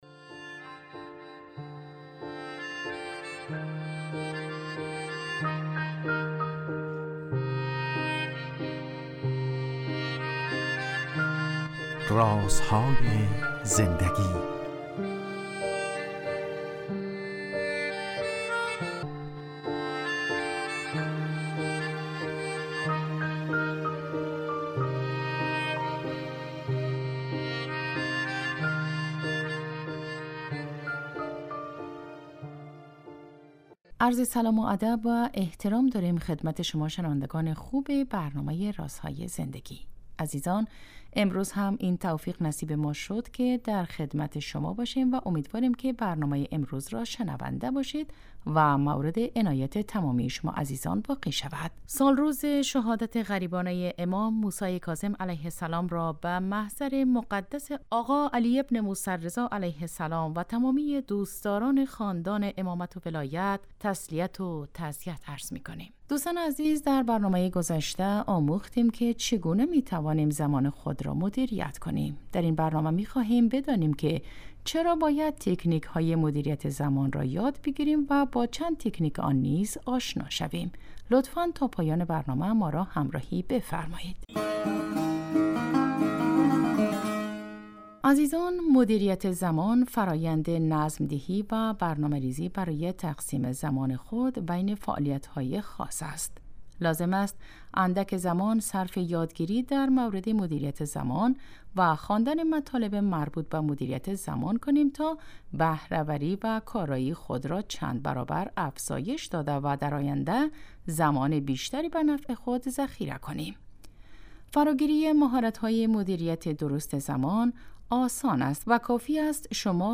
این برنامه به مدت 15 دقیقه هر روز ساعت 11:35 به وقت افغانستان از رادیو دری پخش می شود .